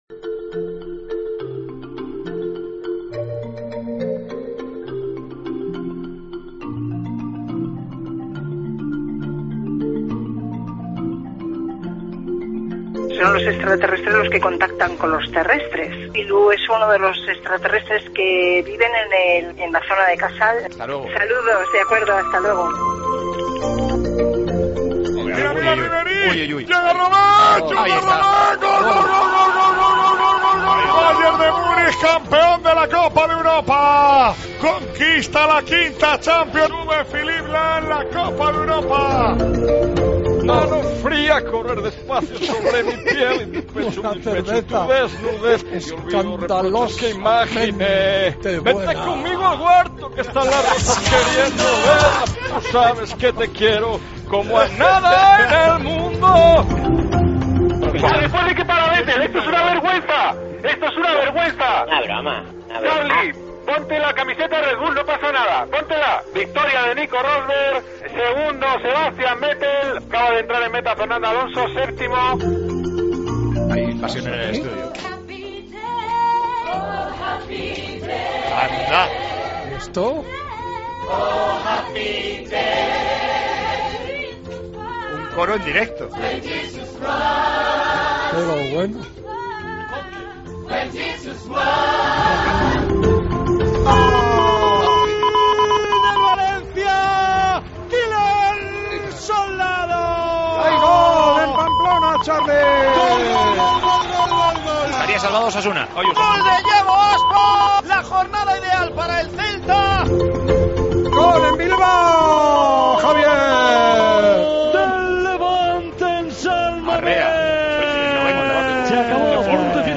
Hablamos con una mujer que ha hablado con extraterrestres, el Bayern consigue su quinta Copa de Europa, un coro de gospel canta en el estudio de Tiempo de Juego, vivimos la penúltima jornada de Liga.
Con Paco González, Manolo Lama y Juanma Castaño